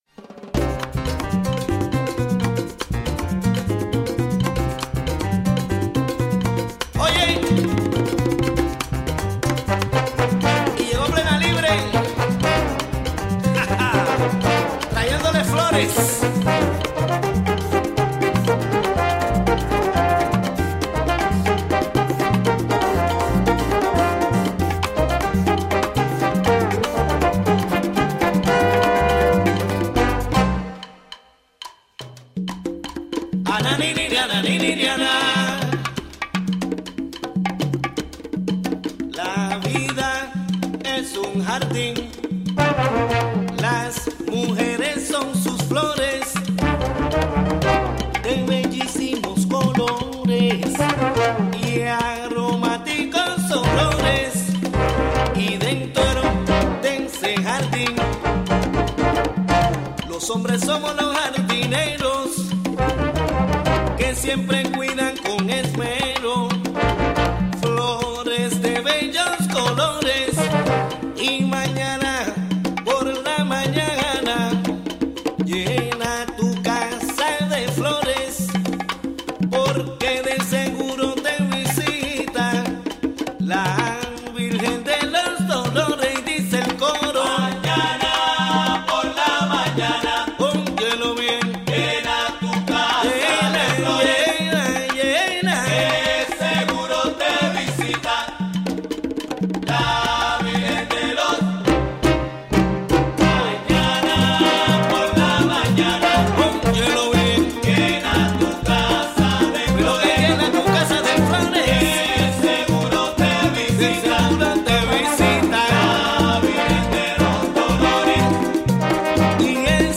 Conversation about President Barack Obama's executive action on immigration that would allow 5 million people to come out of the shadows.